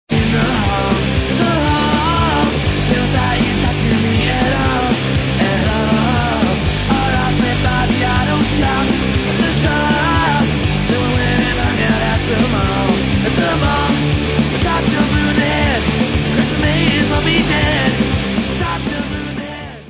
4-chord punk pop